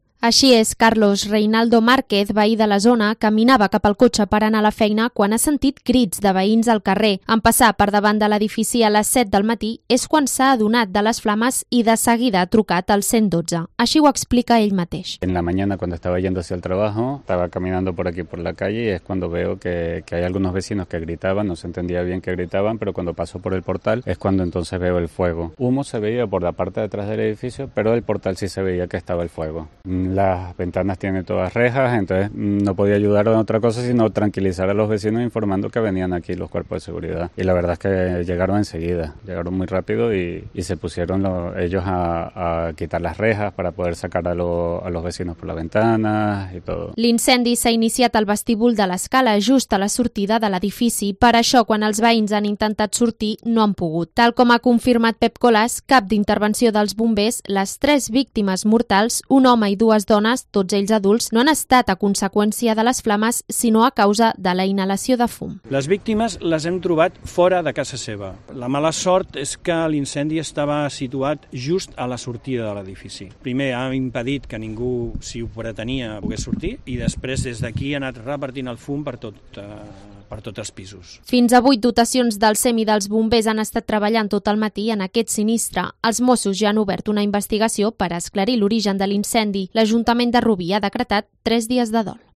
crónica sobre el incendio en una vivienda de Rubí